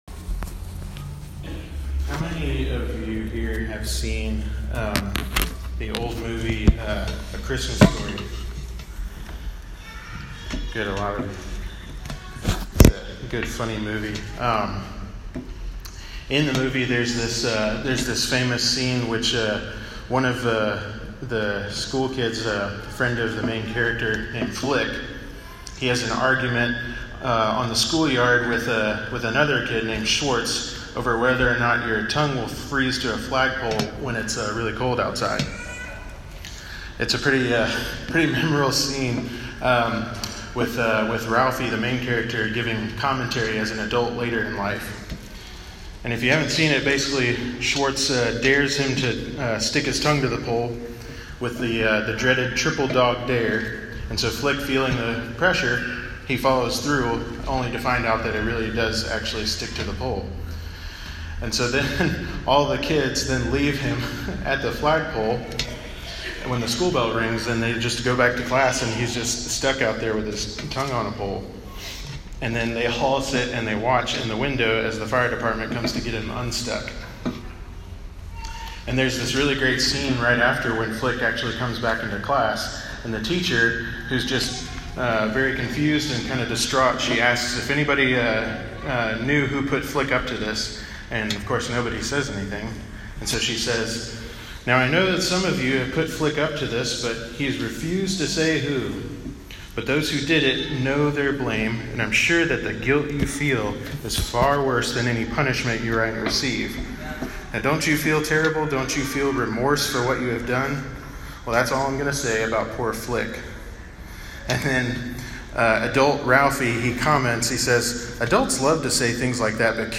Sermon-5.30.21.m4a